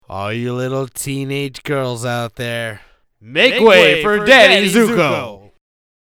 Tags: funny movie quote sound effect